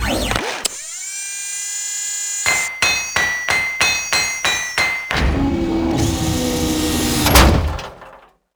cargodrone.wav